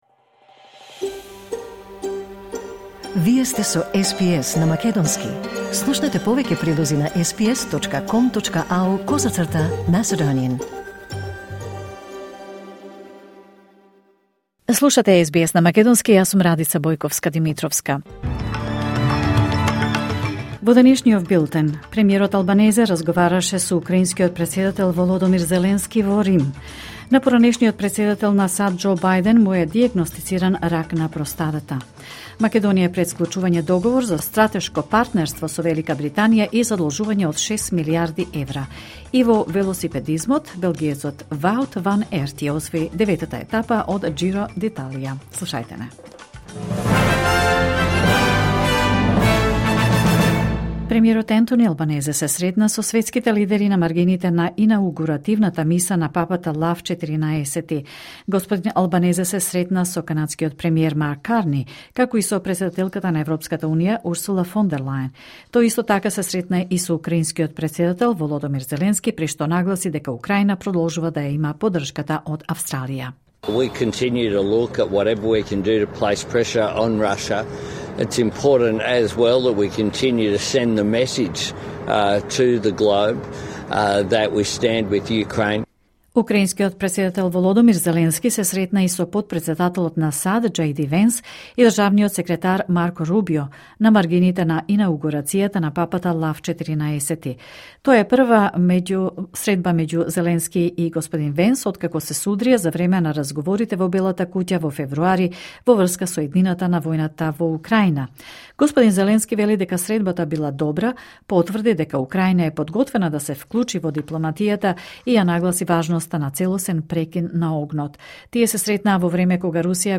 Вести на СБС на македонски 19 мај 2025